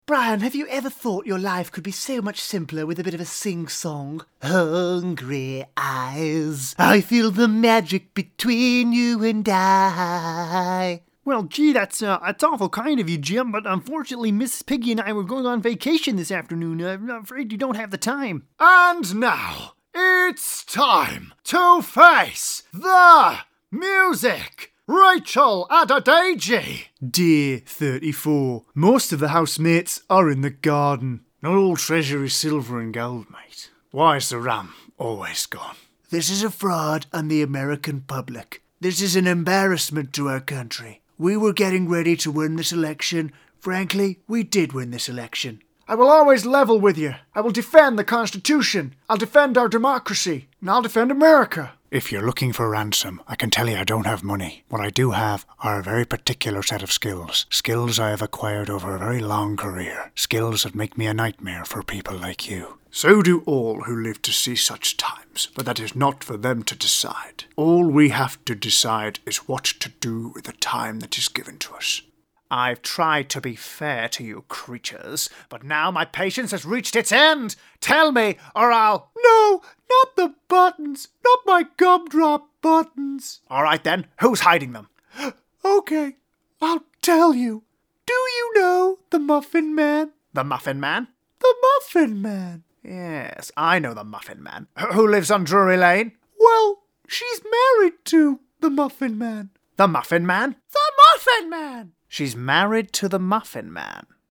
Whether it's natural, a character or an impression, from my home studio I'm able to digitally deliver fast turnarounds of broadcast-quality audio in a variety of formats.
Impressions/Character Showreel